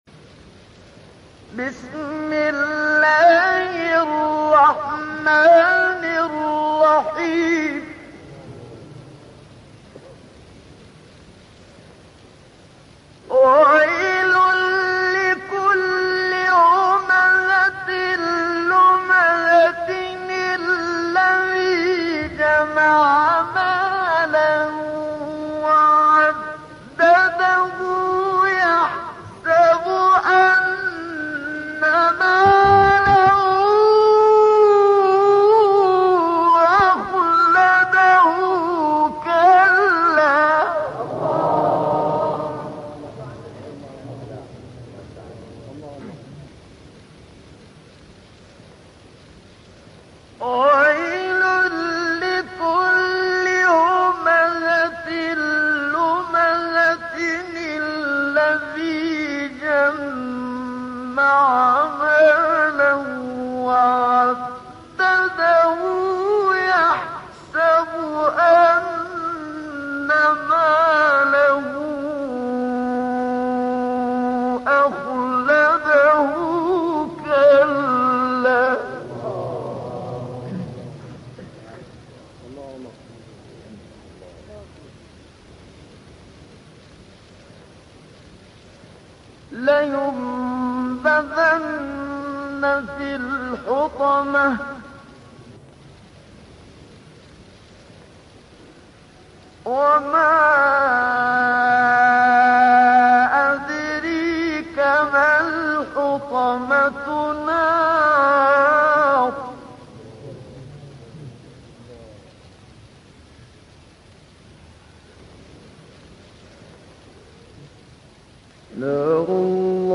خواندن این سوره مرگ بد را از شما دور می‌کند + متن و ترجمه + تلاوت استاد منشاوی